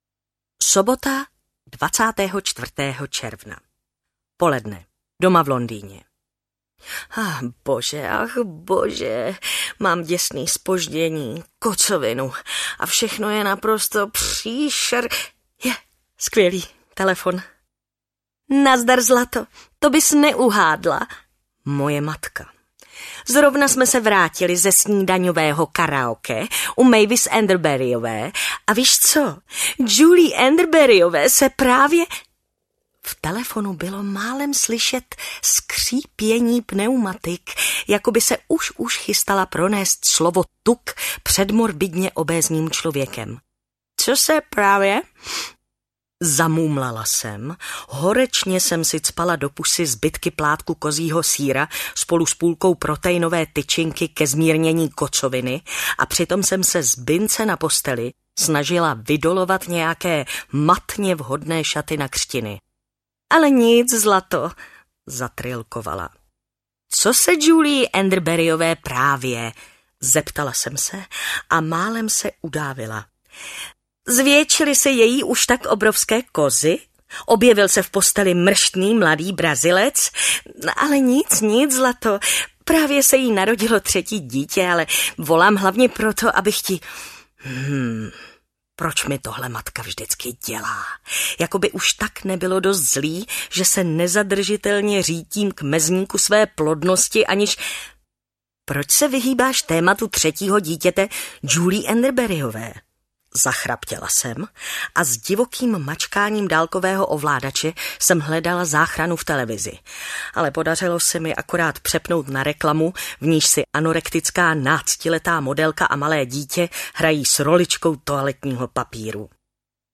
Dítě Bridget Jonesové audiokniha
Ukázka z knihy
• InterpretMartina Hudečková